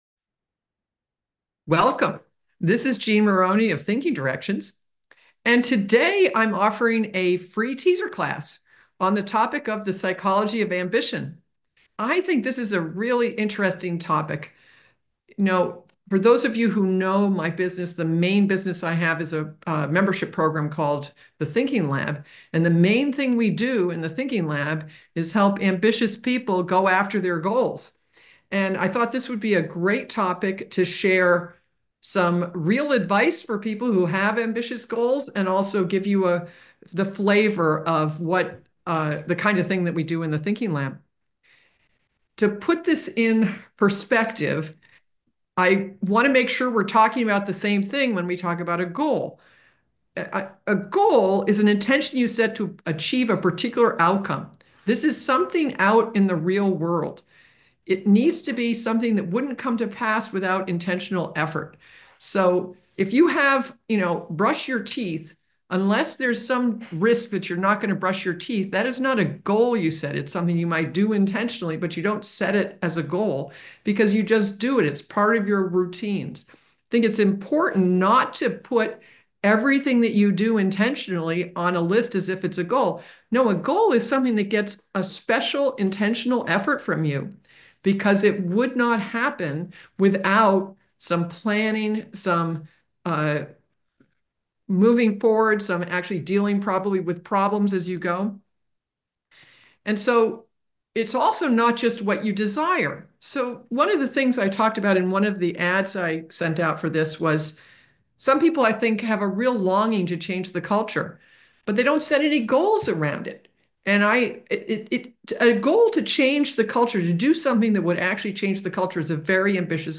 Thank you for registering for this free 1-Hour webinar.